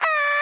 radio_random8.ogg